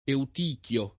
Eutichio [ eut & k L o ]